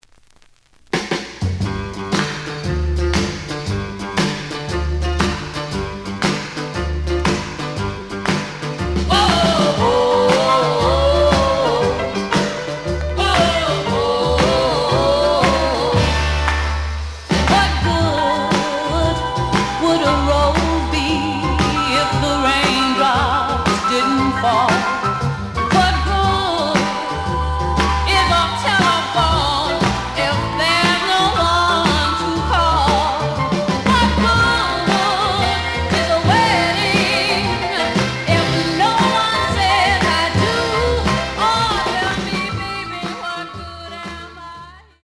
試聴は実際のレコードから録音しています。
●Format: 7 inch
●Genre: Soul, 60's Soul